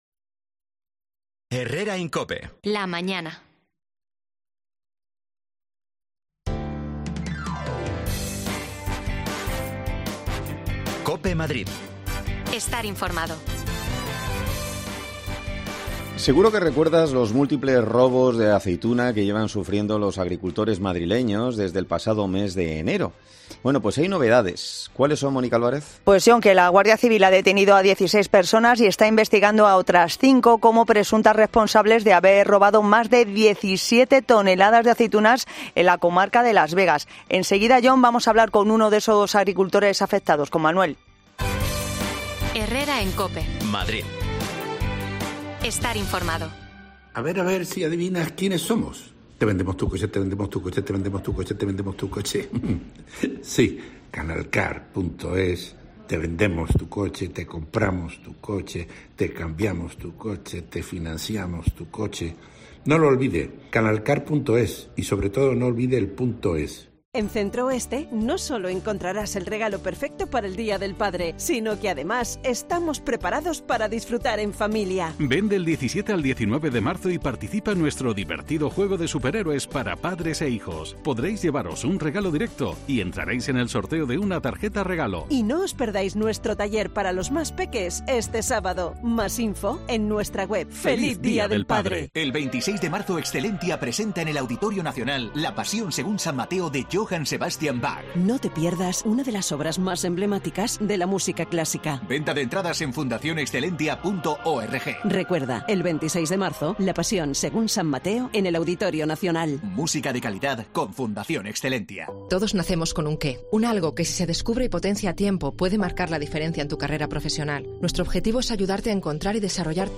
AUDIO: La Guardia Civil desarticula una red de personas dedicada al robo de aceitunas en Madrid. Hablamos con uno de los afectados
Las desconexiones locales de Madrid son espacios de 10 minutos de duración que se emiten en COPE , de lunes a viernes.